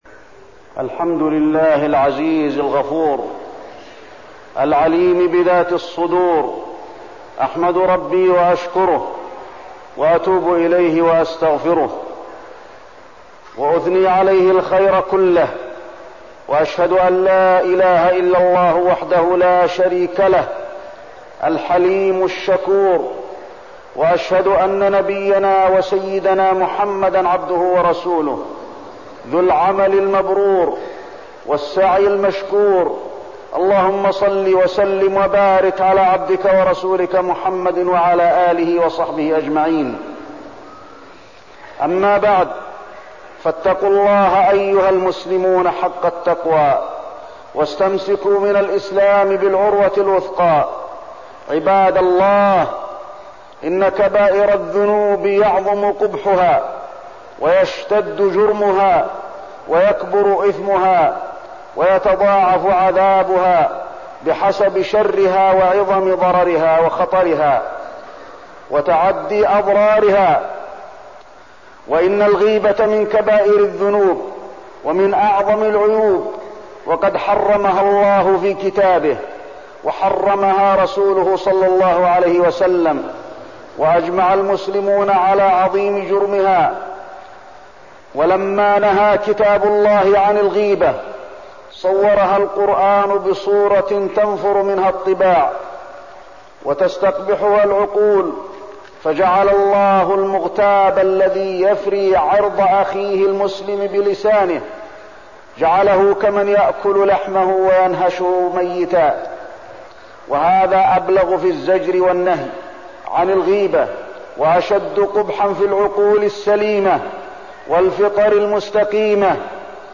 تاريخ النشر ٢٢ محرم ١٤١٥ هـ المكان: المسجد النبوي الشيخ: فضيلة الشيخ د. علي بن عبدالرحمن الحذيفي فضيلة الشيخ د. علي بن عبدالرحمن الحذيفي الغيبة والنميمة